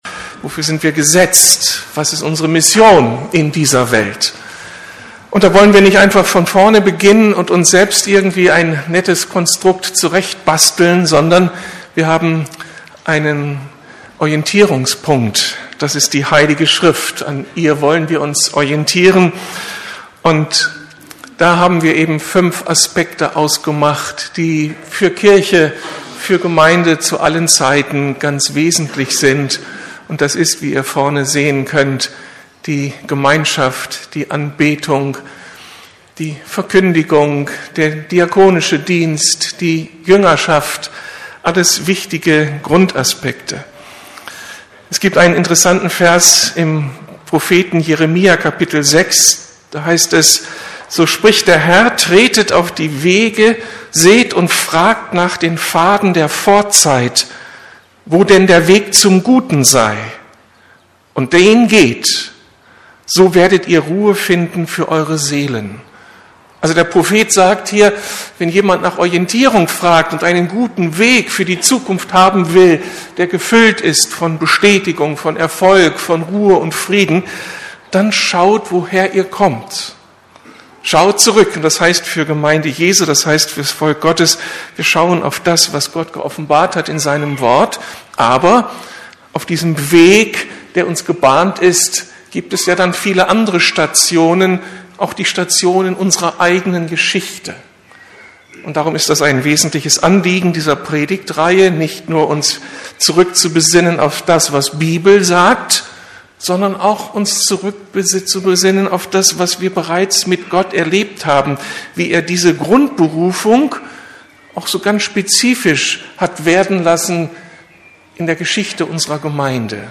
Berufen zur Anbetung ~ Predigten der LUKAS GEMEINDE Podcast